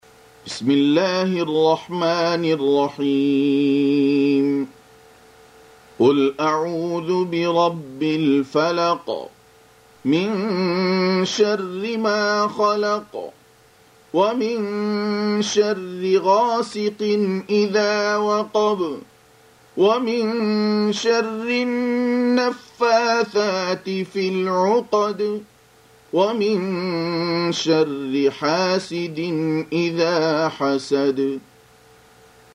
Surah Sequence تتابع السورة Download Surah حمّل السورة Reciting Murattalah Audio for 113. Surah Al-Falaq سورة الفلق N.B *Surah Includes Al-Basmalah Reciters Sequents تتابع التلاوات Reciters Repeats تكرار التلاوات